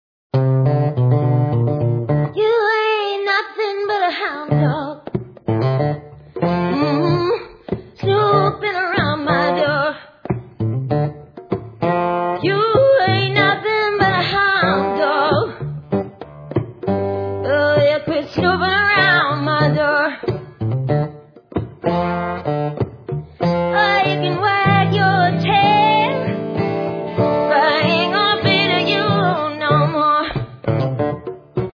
From bouncy and boisterous to warm and furry